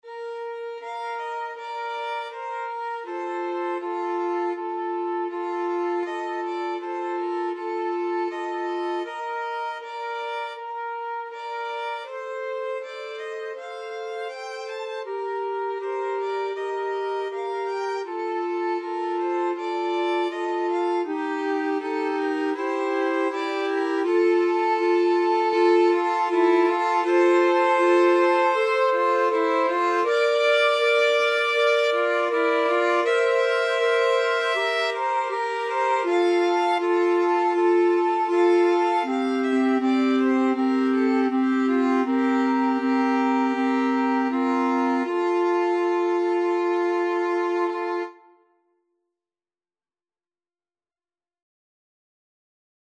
Flute-Clarinet-Violin version
4/4 (View more 4/4 Music)
andante Largo
Classical (View more Classical Flute-Clarinet-Violin Music)